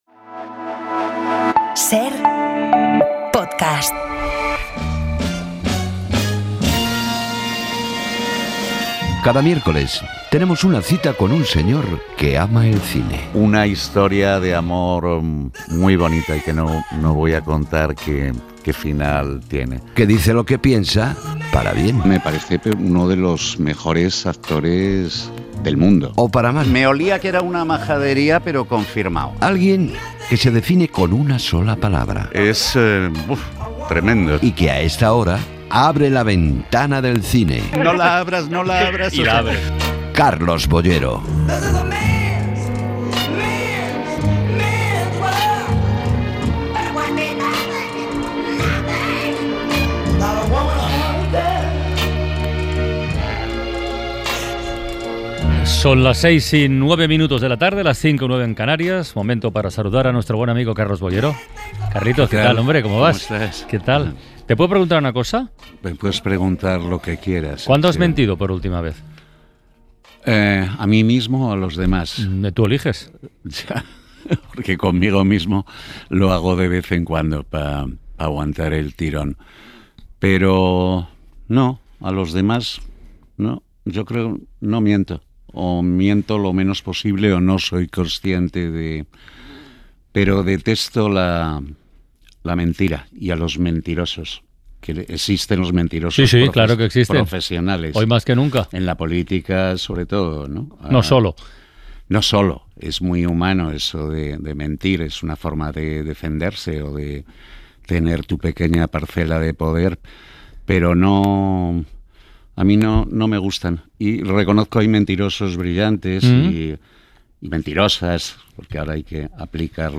Nuestro crítico de cine Carlos Boyero visita 'La Ventana' para hablarnos de los nuevos estrenos que llegan a la cartelera esta semana.